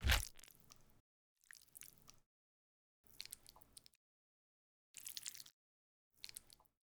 bleed 2.wav